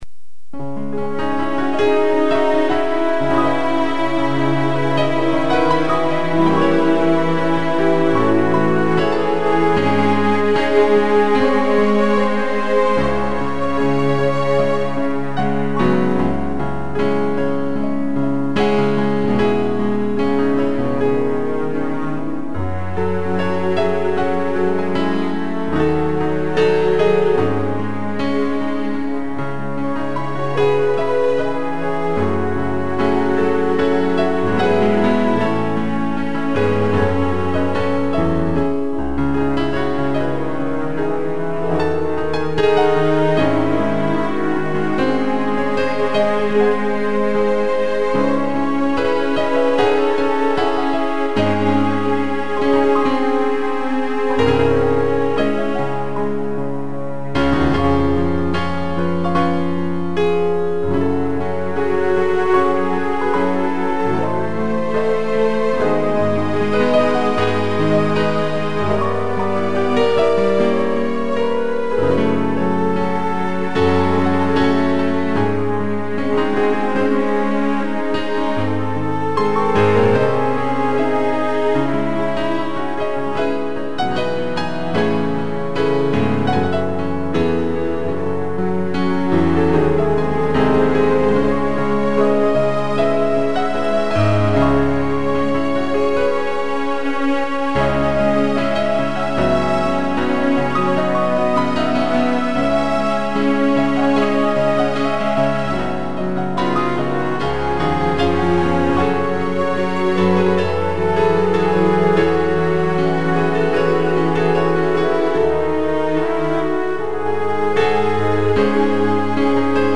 2 pianos e tutti
(instrumental)